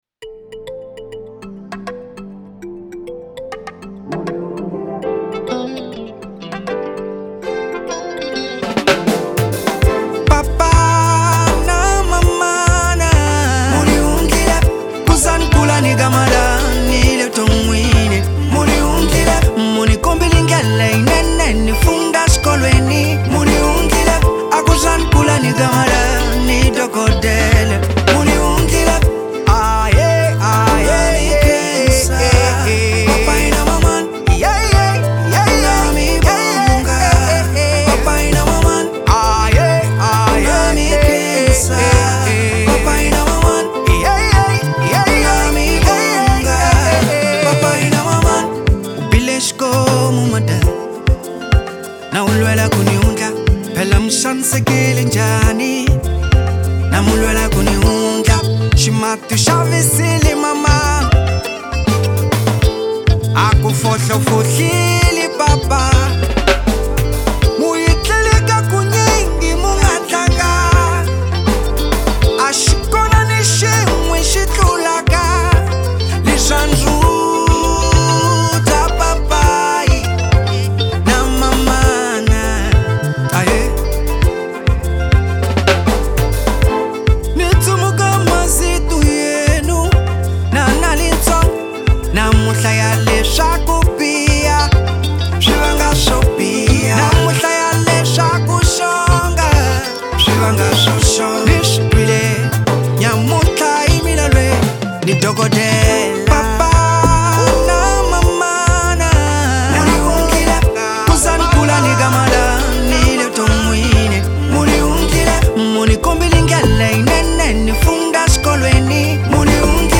African Highlife Ano de Lançamento